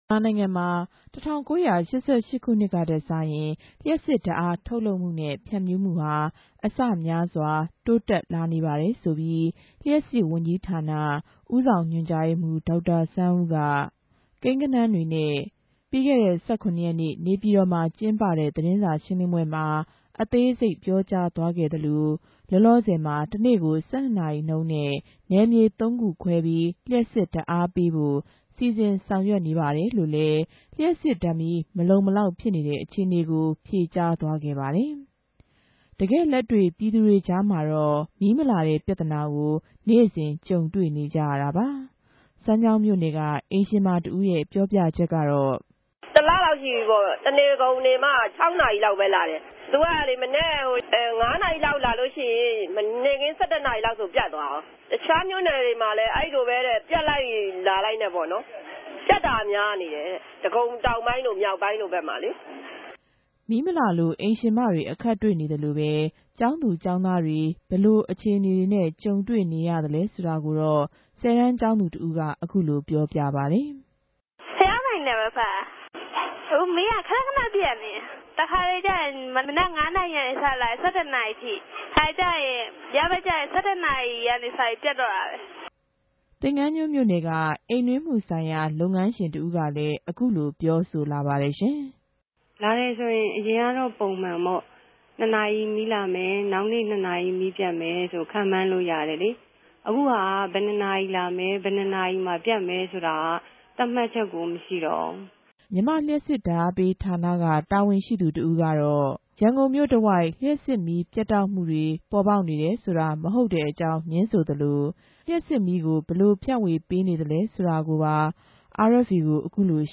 ဗန်ကောက်္ဘမိြႚကနေ တင်ူပထားပၝတယ်။